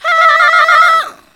SCREAM11  -L.wav